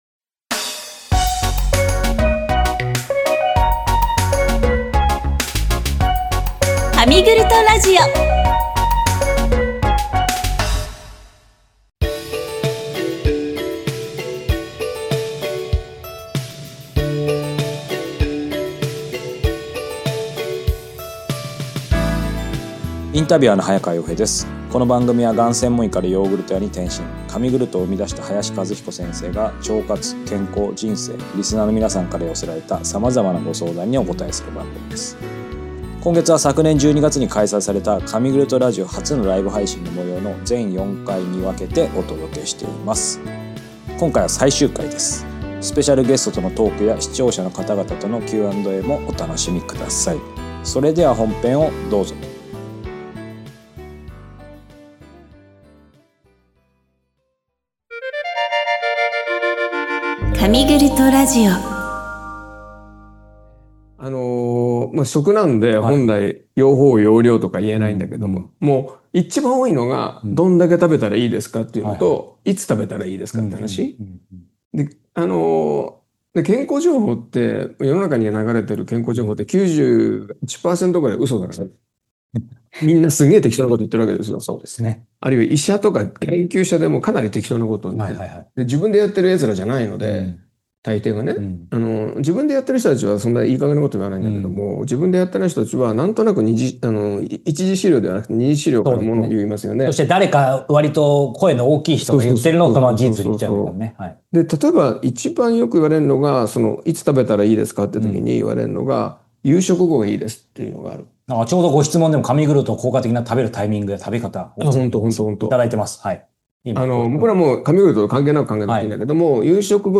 神ラジ初のライブ配信・全4回シリーズ、ついに最終回。